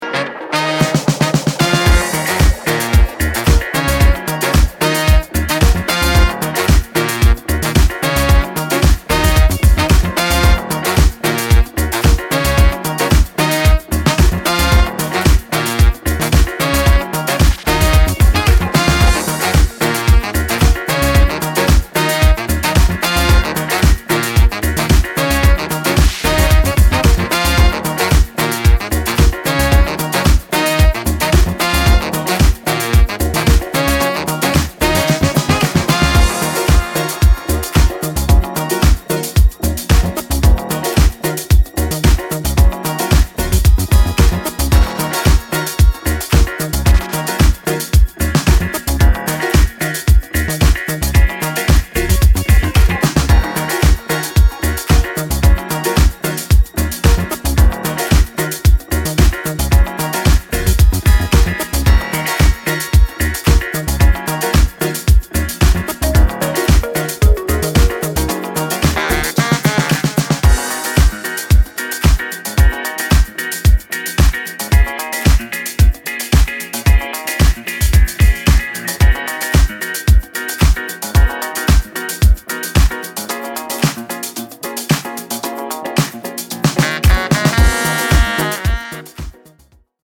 ジャンル(スタイル) AFRO HOUSE